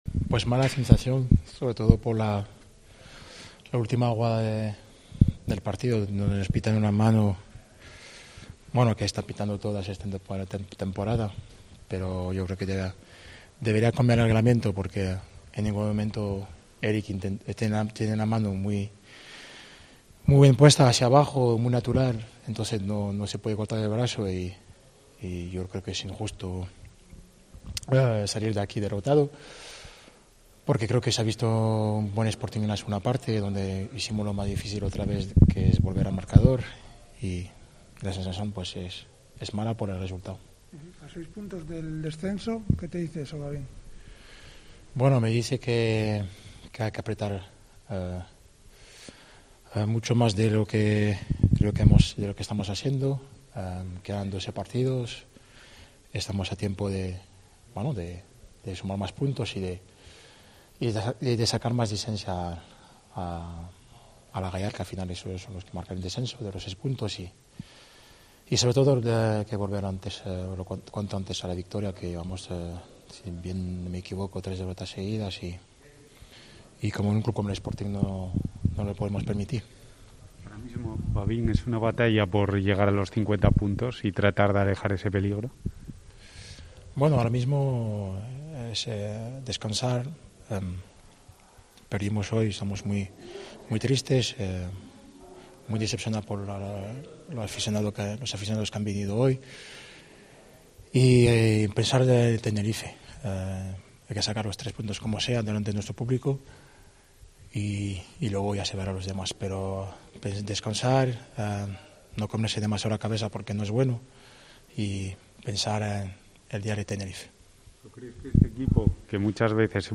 en zona mixta tras caer en el Reale Arena